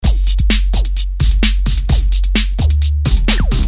Electro rythm - 130bpm 28